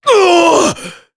Gau-Vox_Damage_jp_03.wav